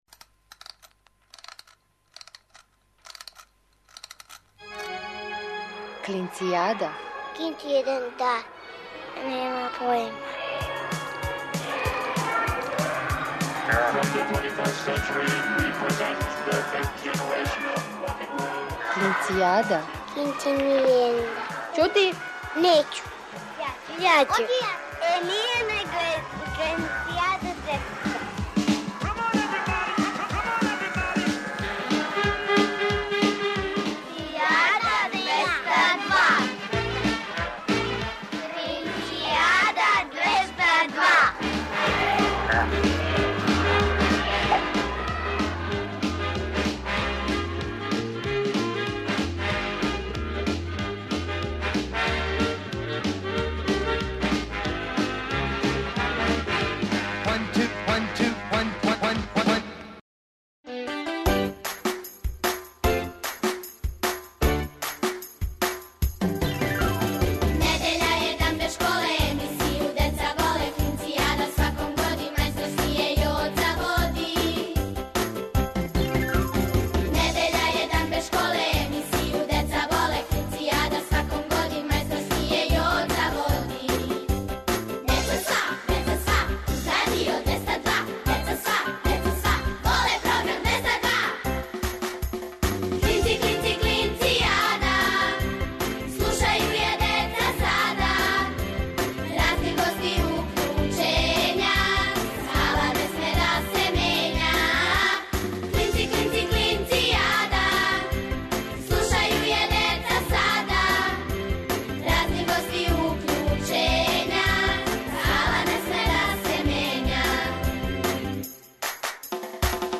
О деци за децу, емисија за клинце и клинцезе, и све оне који су у души остали деца.